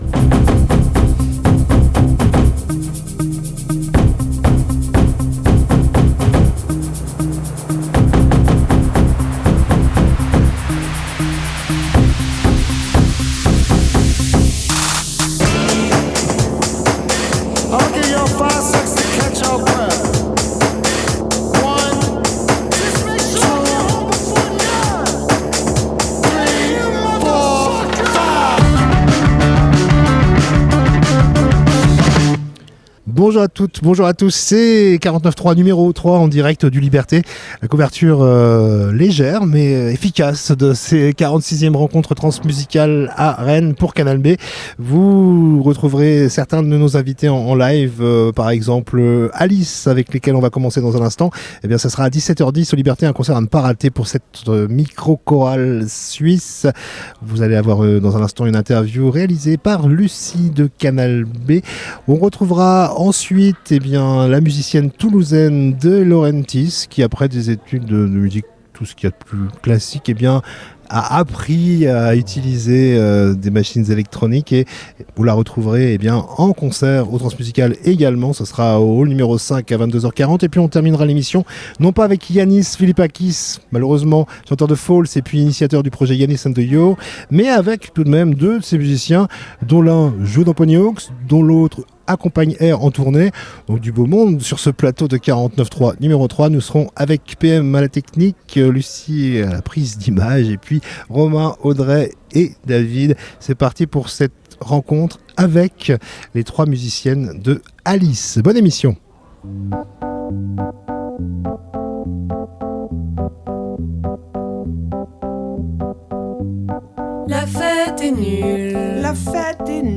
Samedi 7 décembre, c'était la dernière des trois émissions spéciales réalisées en direct des Trans musicales au Liberté.